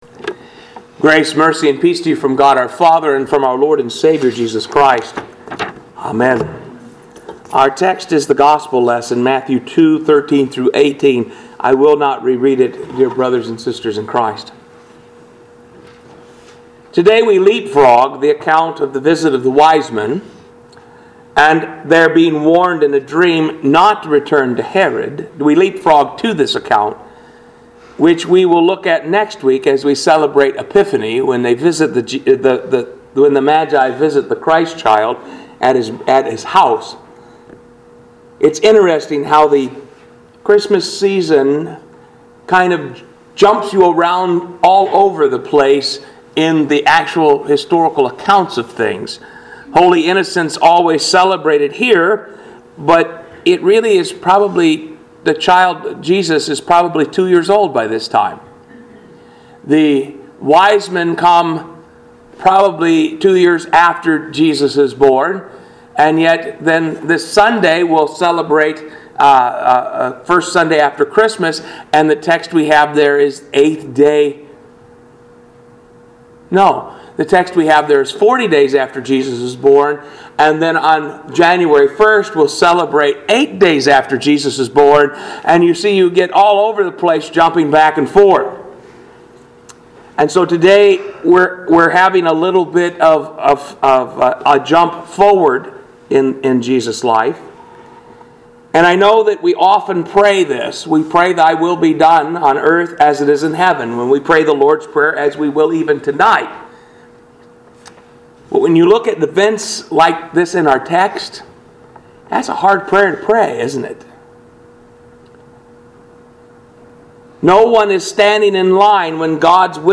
Pilgrim Ev. Lutheran Church - Sermons